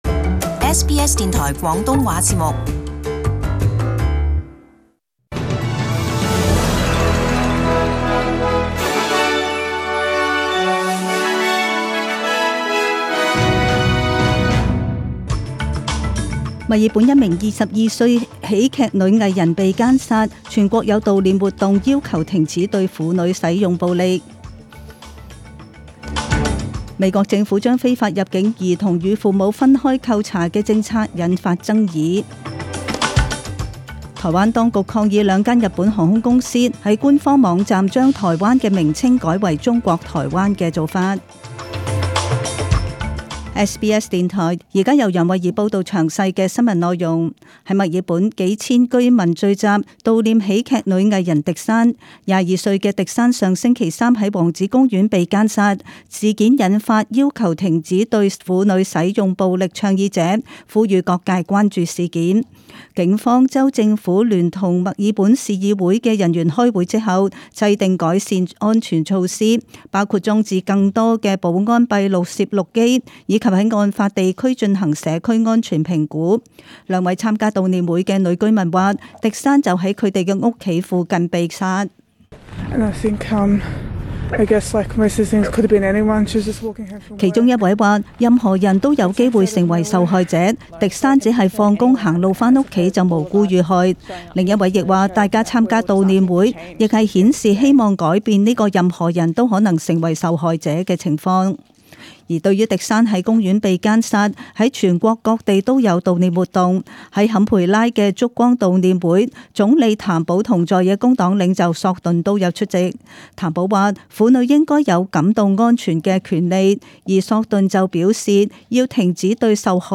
SBS中文新闻 （六月十九日）
请收听本台为大家准备的详尽早晨新闻。